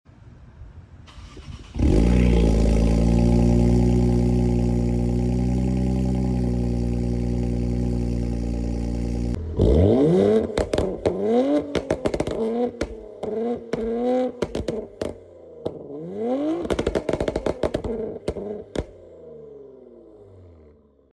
Modded Q50 Cold Start, Pops sound effects free download
Pops Mp3 Sound Effect Modded Q50 Cold Start, Pops & Revs in Burble Map, & Idle Sounds!!